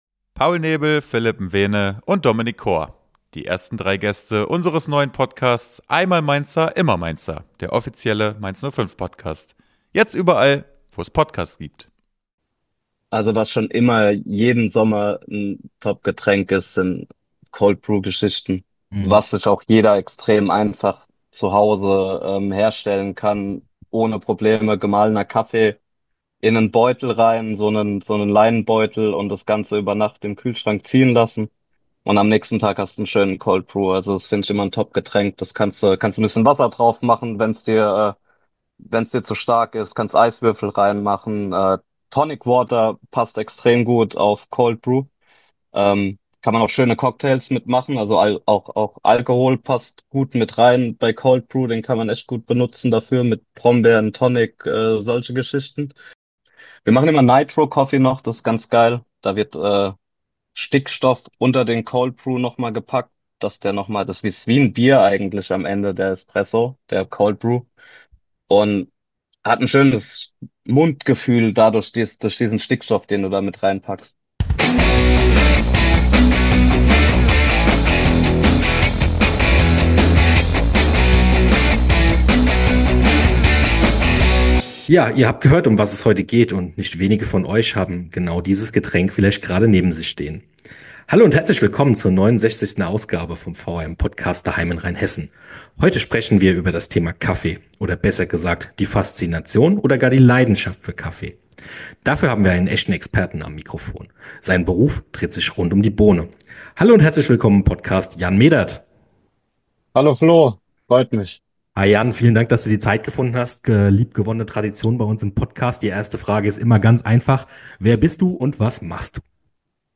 Ein Gespräch mit Barista